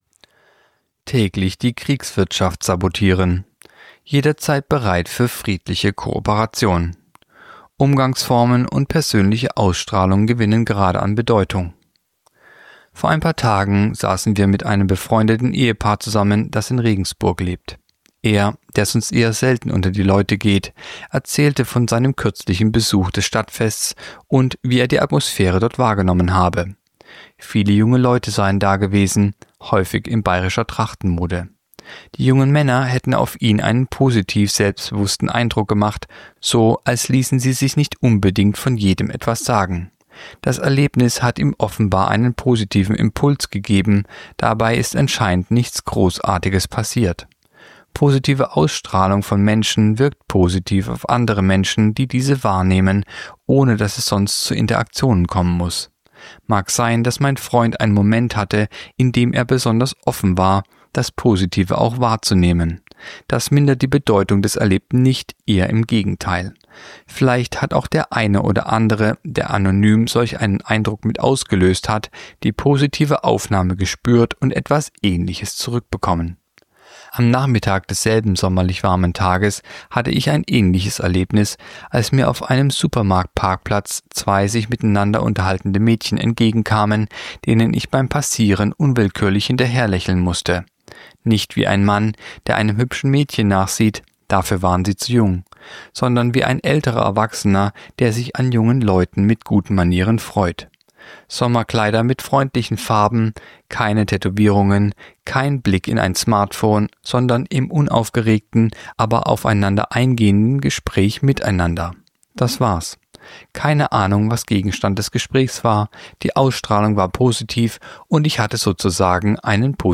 (Sprecher)